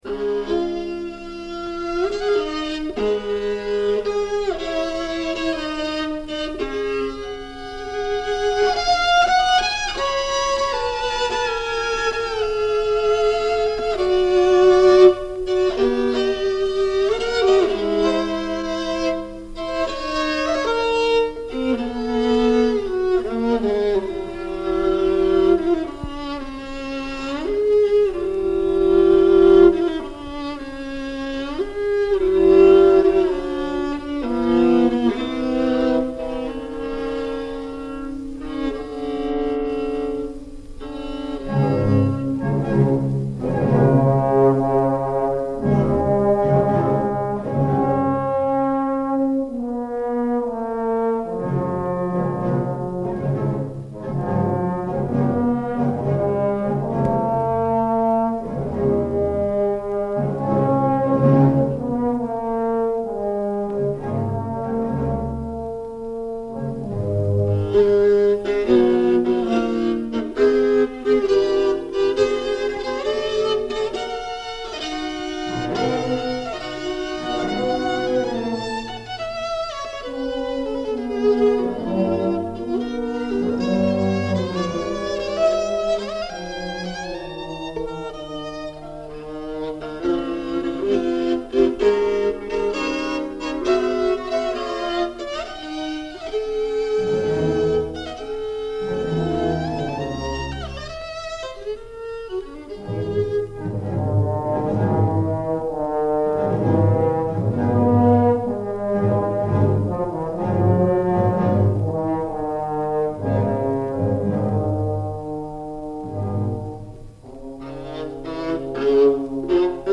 Radio Studio, Bern Switzerland
for viola and orchestra
Radio Studio performance